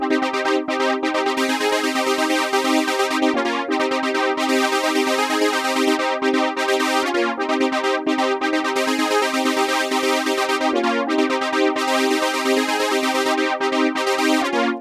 FILTER OBIE 1.wav